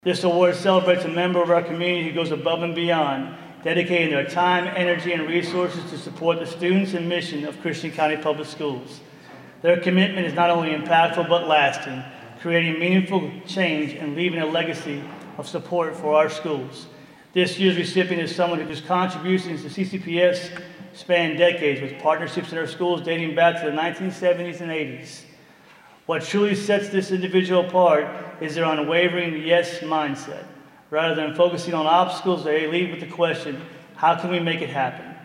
Wednesday night, some employees, their families, and school officials gathered to celebrate several dozen coworkers.
Superintendent Chris Bentzel says the work they do is more successful with community support, which is why they created the All In Award.